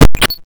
sfx_train_door_open.wav